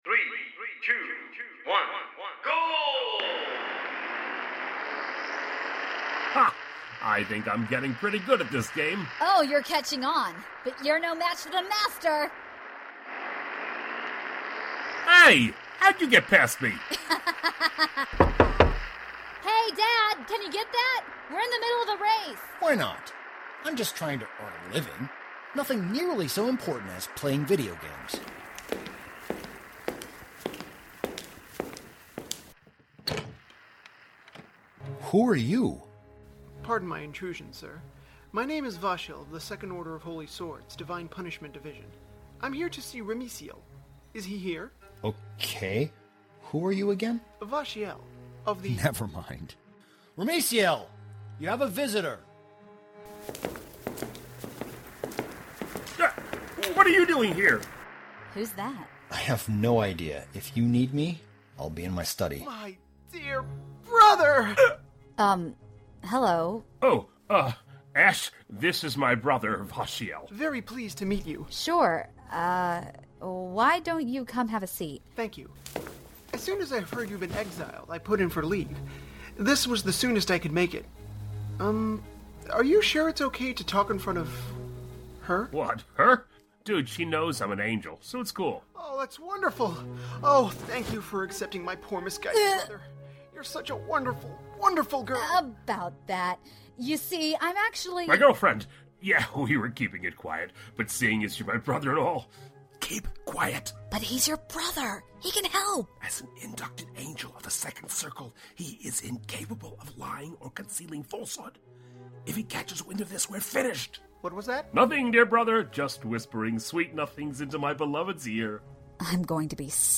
Misfile - the Radioplay: episode 5